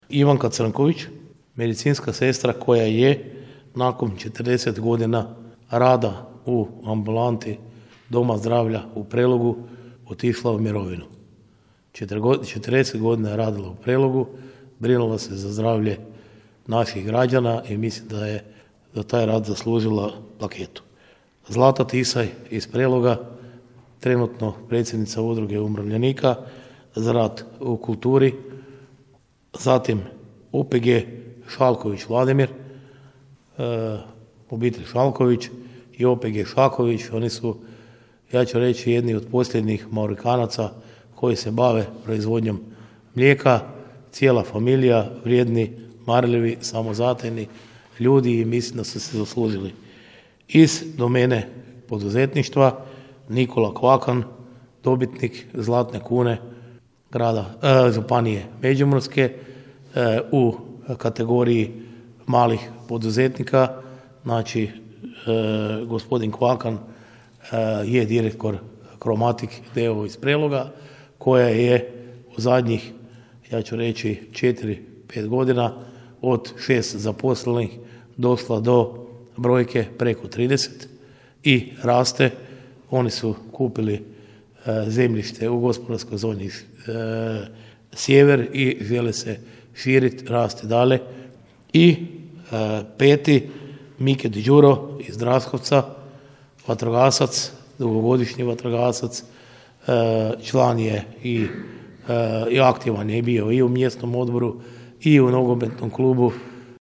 Na sjednici Gradskog vijeća prva točka dnevnog reda bilo je donošenje odluke o dodjelih javnih priznanja Grada za 2021. godinu.
Prijedloge koje je dalo Povjerenstvo za dodjelu javnih priznanja obrazložio je gradonačelnik Ljubomir Kolarek, a prenosimo iz našeg informativnog programa.